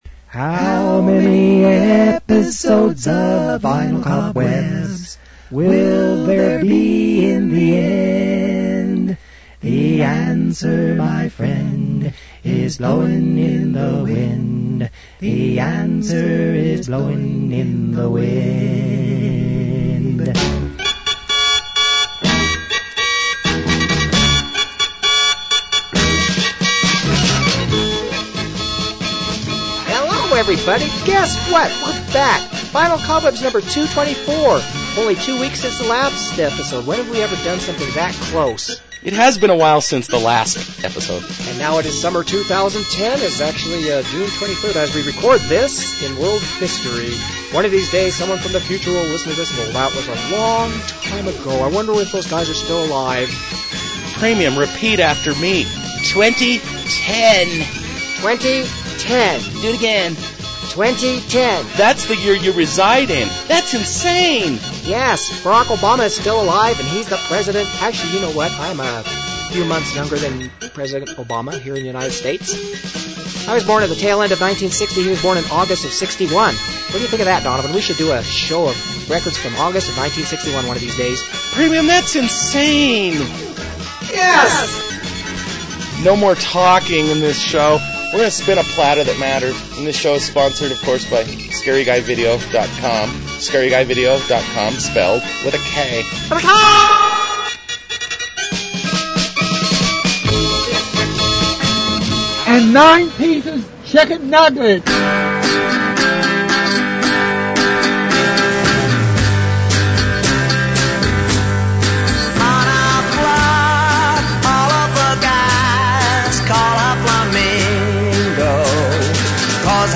Vinyl Cobwebs: Home Of Uncommon Oldies Radio